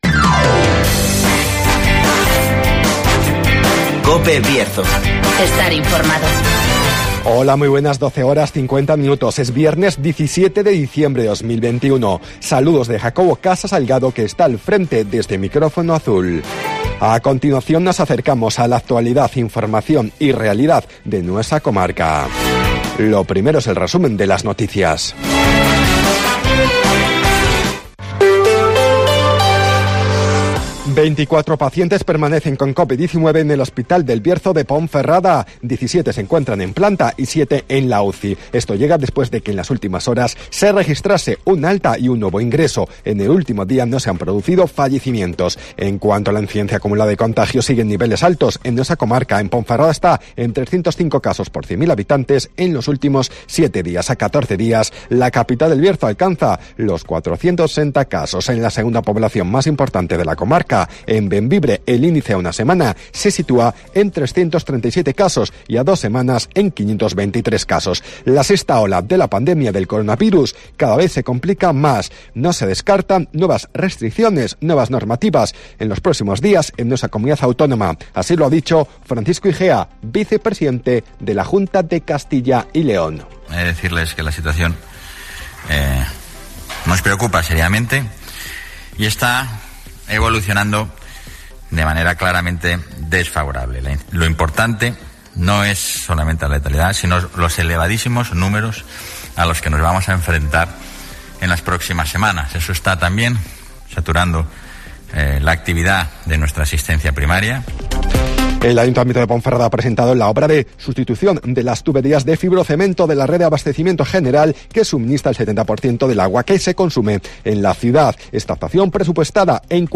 Avance informativo, El Tiempo y Agenda